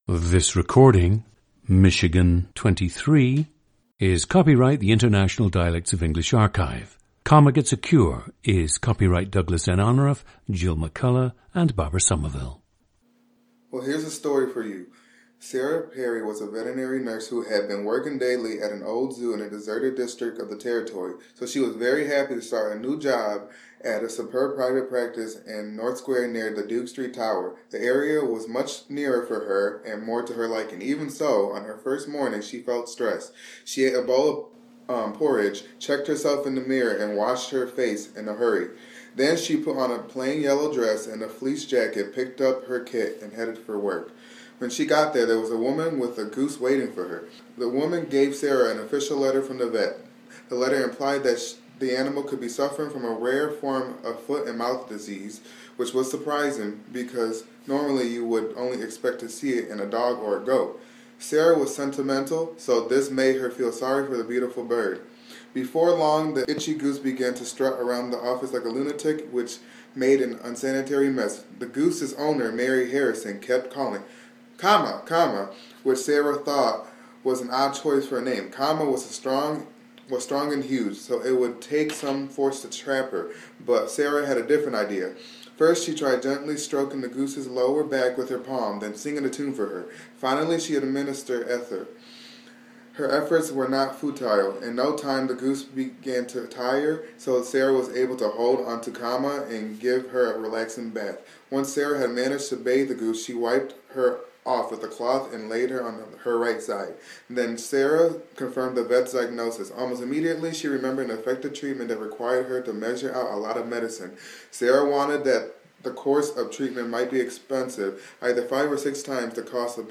PLACE OF BIRTH: Lansing, Michigan
GENDER: male
The speaker’s first language is English, but he learned some Spanish as a second language.
ORTHOGRAPHIC TRANSCRIPTION OF UNSCRIPTED SPEECH:
The recordings average four minutes in length and feature both the reading of one of two standard passages, and some unscripted speech.